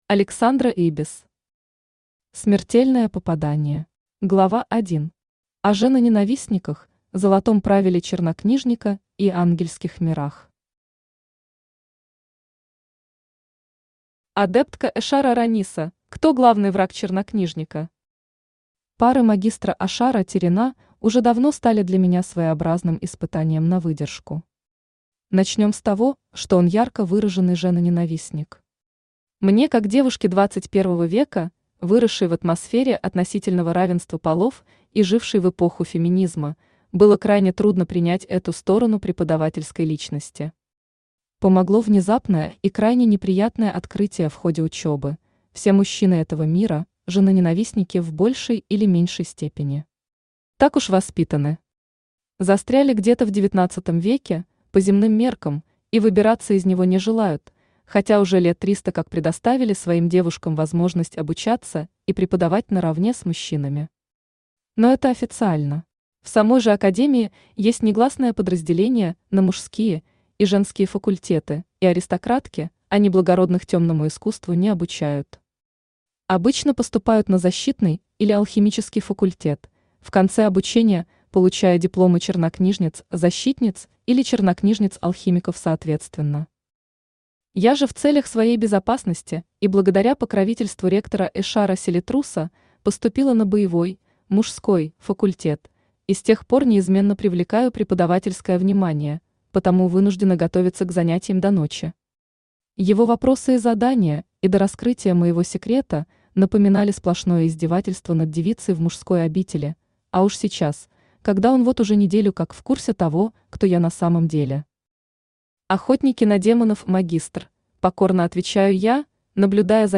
Аудиокнига Смертельное попадание | Библиотека аудиокниг
Aудиокнига Смертельное попадание Автор Александра Ибис Читает аудиокнигу Авточтец ЛитРес.